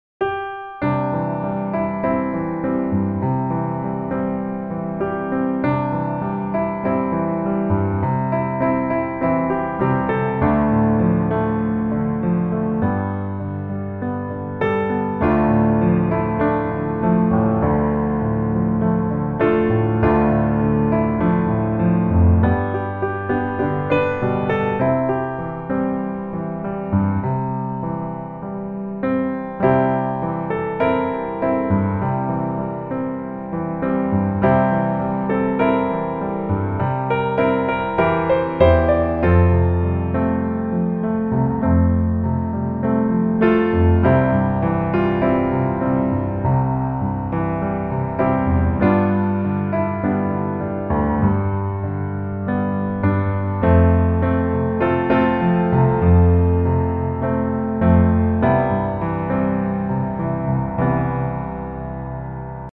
C Majeur